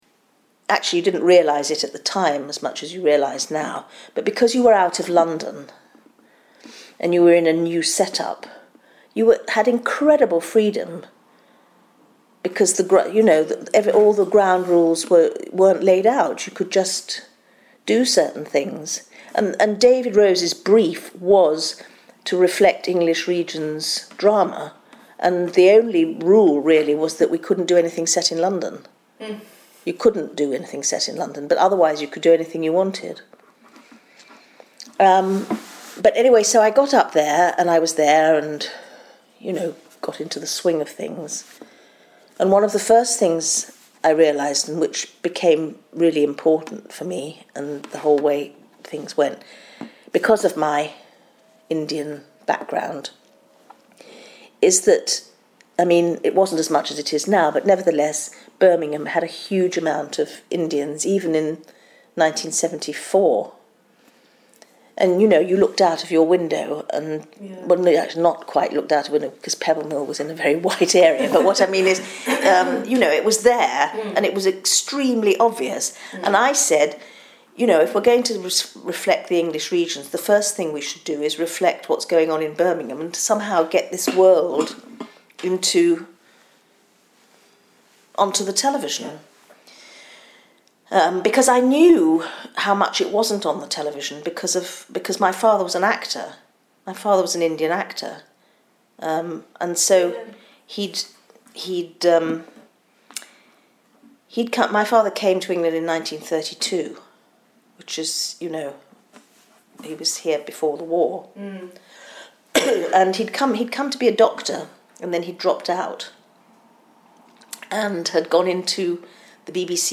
including extracts from an interview